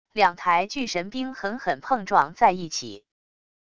两台巨神兵狠狠碰撞在一起wav音频